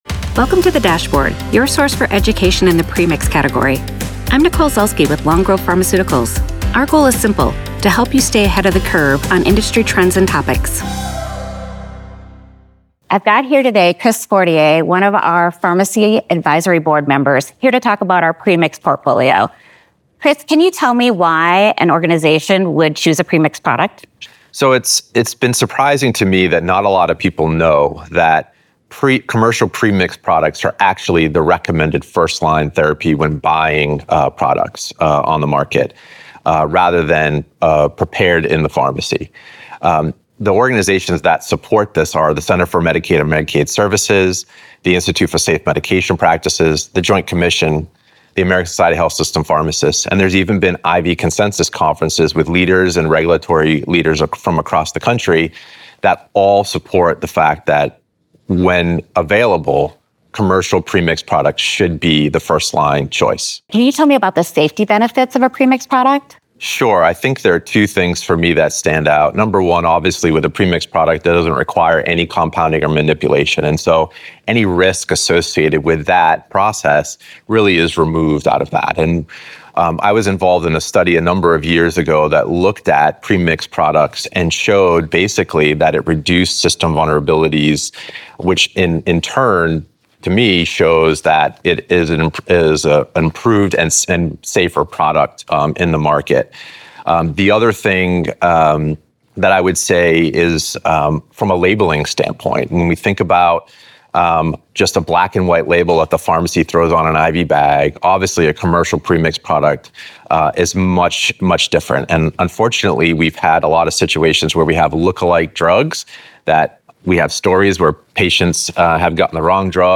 Long-Grove-Pharma-Fireside-Chat-Full-Audio-MP3-File.mp3